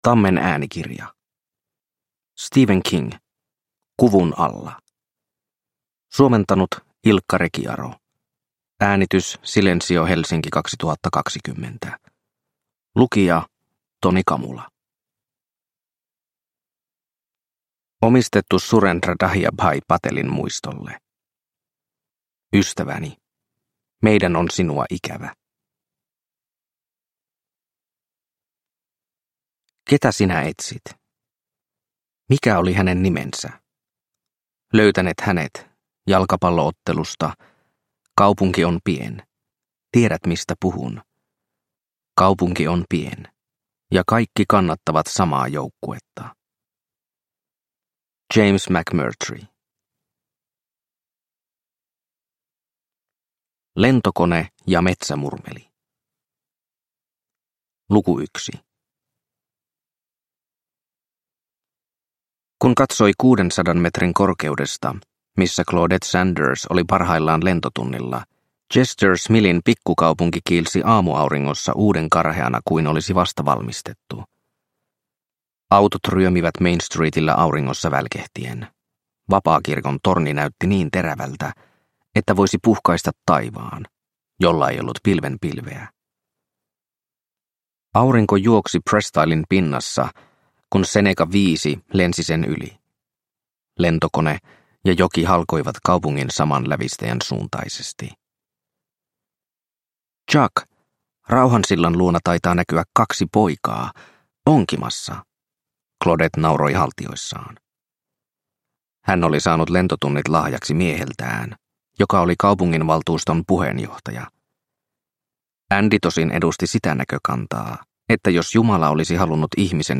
Kuvun alla – Ljudbok – Laddas ner